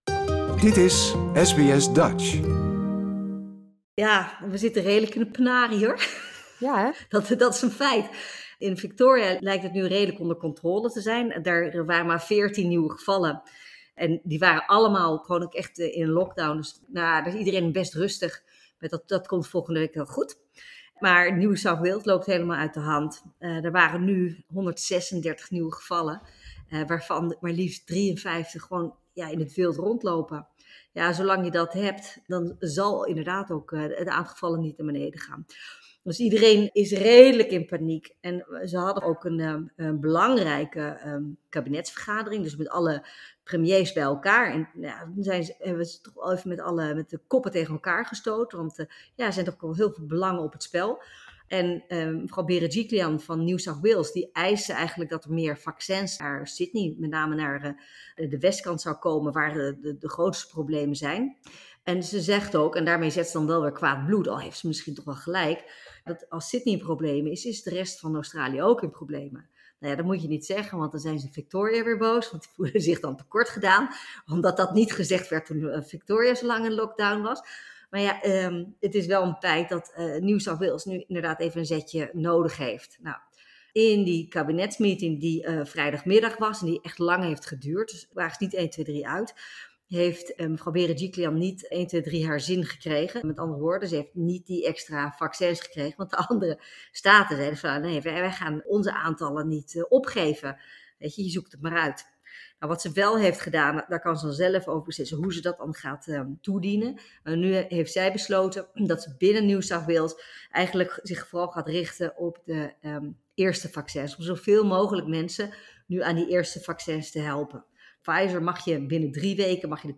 Wat nu en hoe reageren andere staten hierop? Dat en meer bespraken we met onze vaste politiek commentator